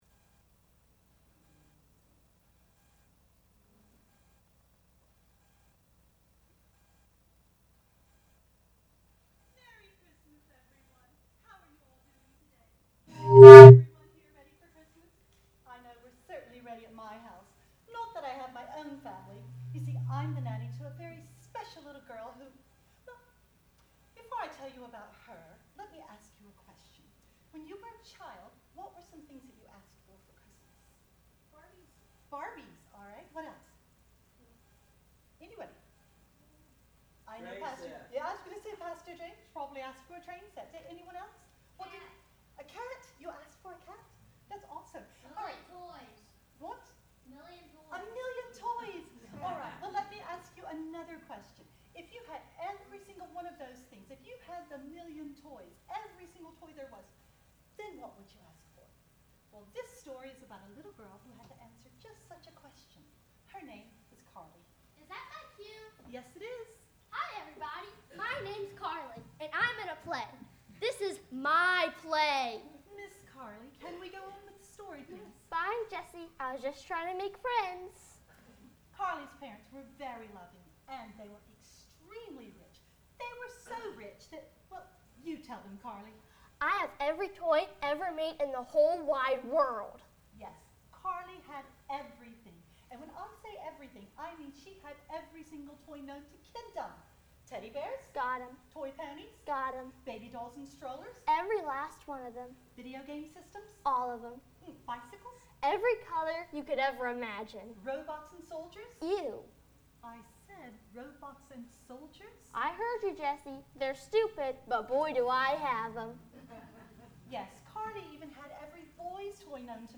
Christmas Plays